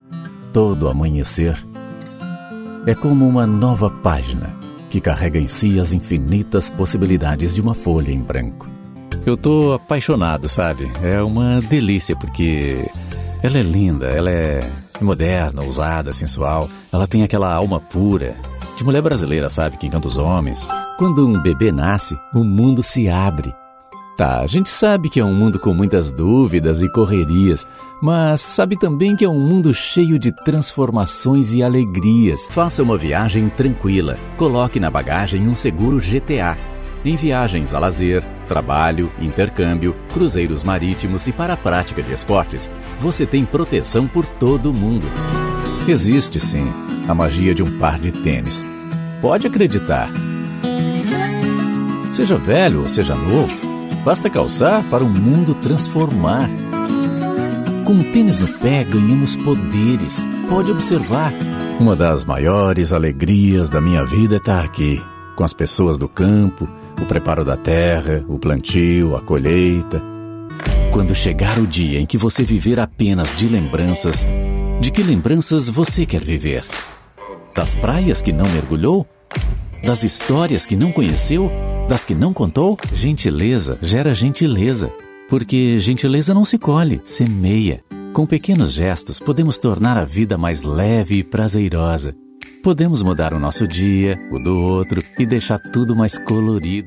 Portuguese (Brazilian)
Maternal
Natural
Adult